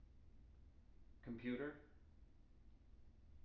wake-word